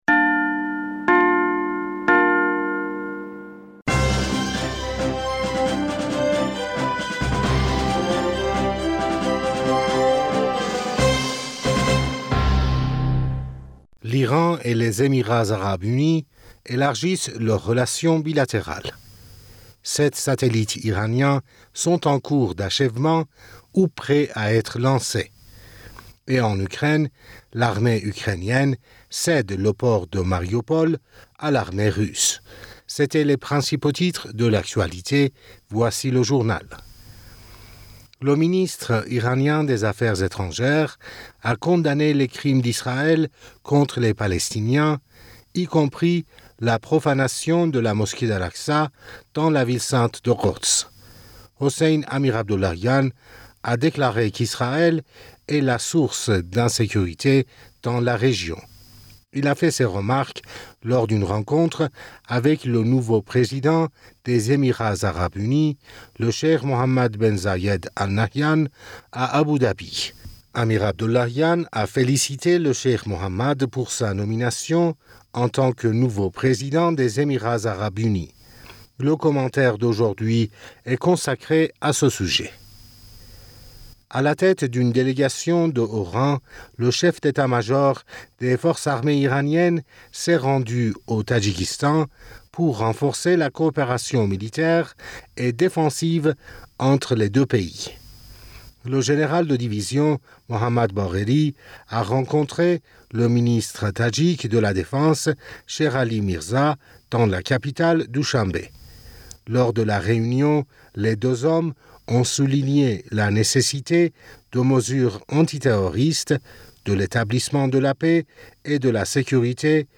Bulletin d'information Du 17 Mai 2022